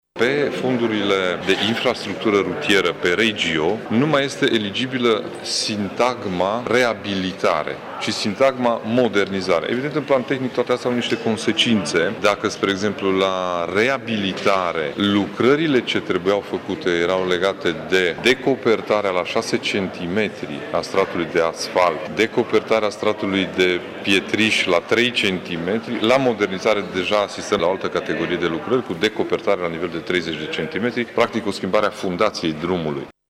Ciprian Dobre a explicat că acest lucru trebuia făcut deoarece s-au schimbat normele europene privind accesarea fondurilor europene: